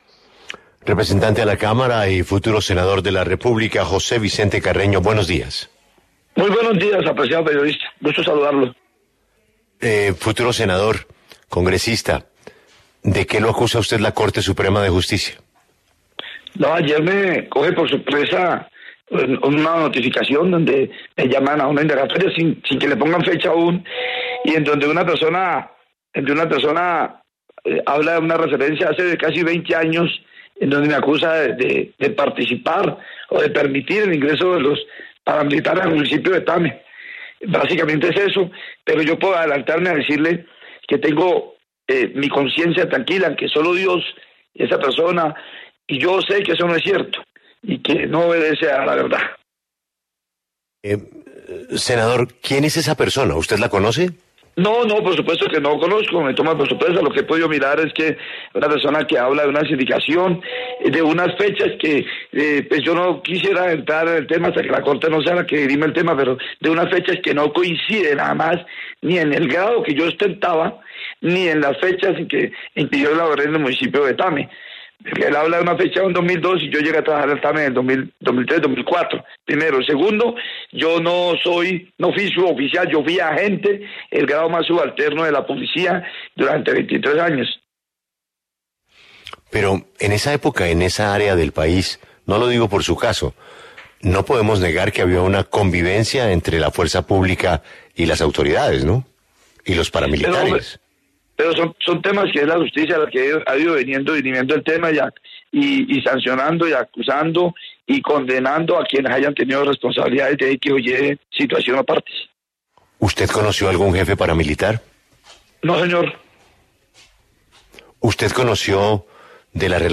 En diálogo con La W, José Vicente Carreño, representante a la Cámara y senador electo, se refirió al llamado a indagatoria que le hizo la Corte Suprema de Justicia por una presunta relación con los paramilitares en Arauca.